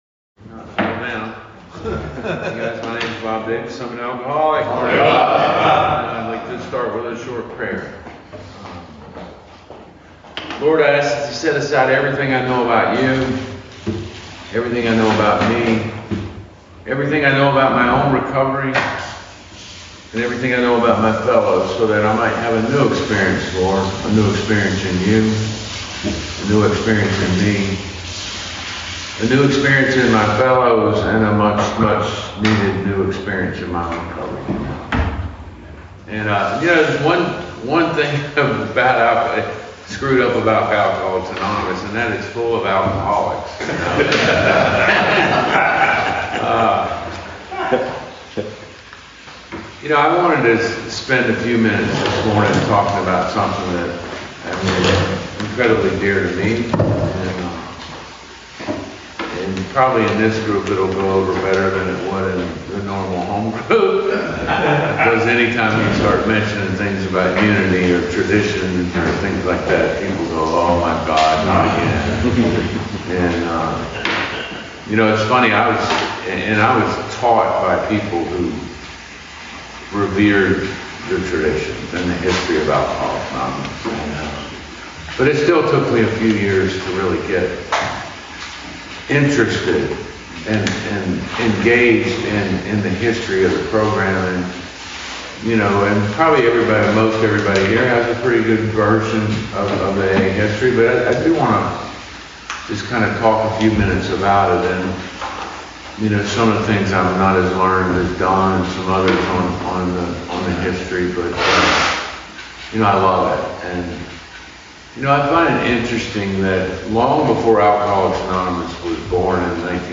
Retreat Sessions November 2021 – Alcoholics Anonymous Speaker Recordings